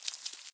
step1.ogg